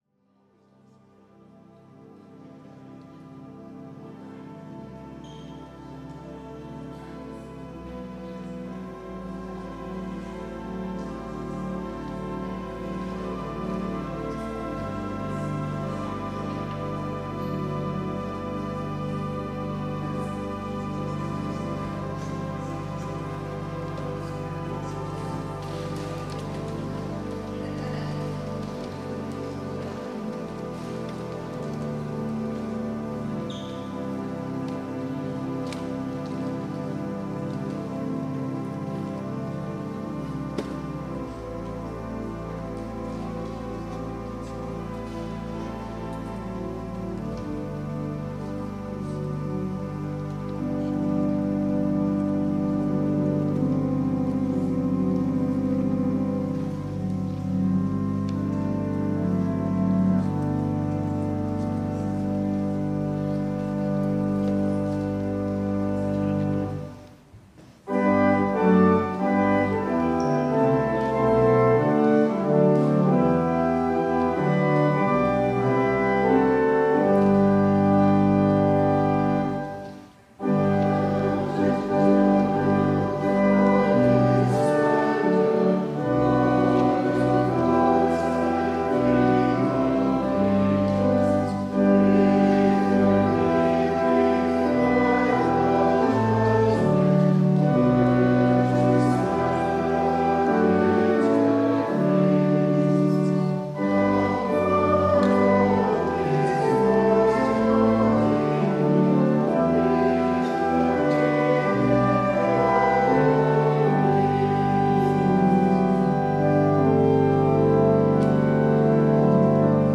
Worship and Sermon audio podcasts
Podcast from Christ Church Cathedral Fredericton
WORSHIP - 10:30 a.m. Fifth after Pentecost